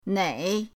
nei3.mp3